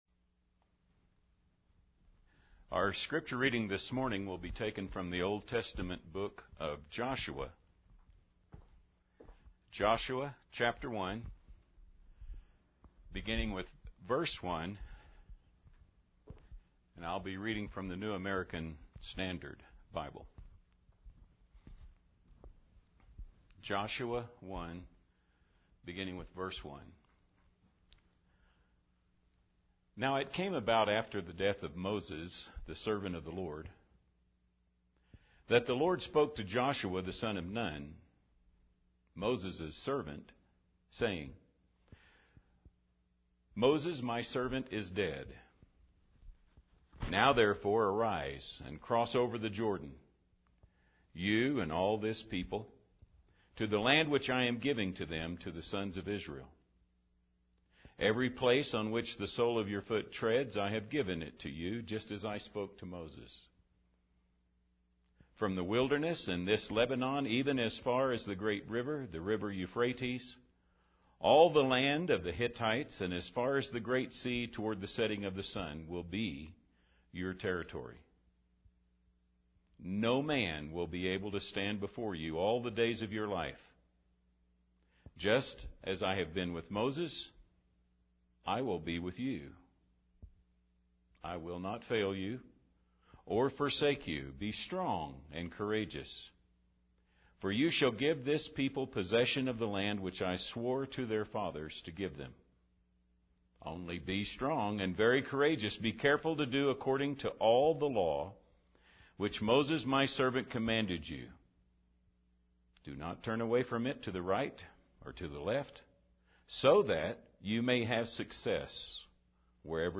By the way good job preaching to an empty auditorium.